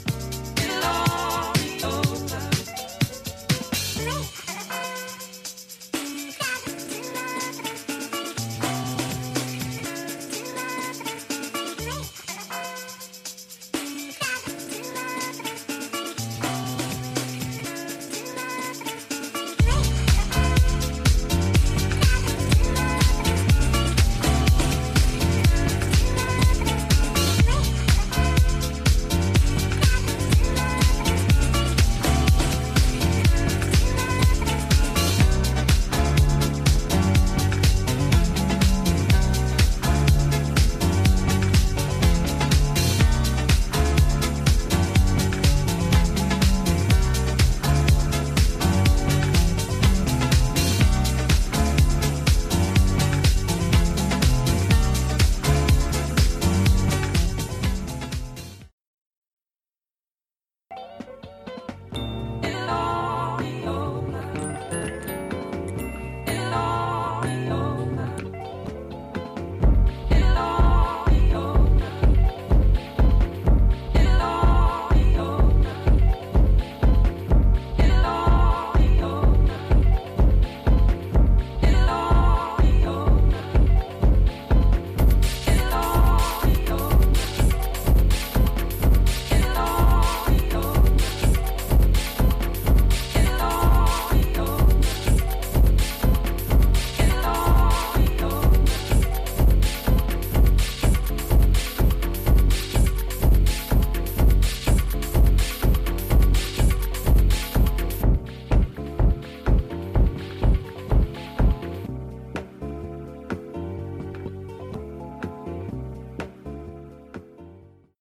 The British producer's distinctively twisted, lo-fi sound